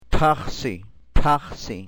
Aspirated Occlusives ph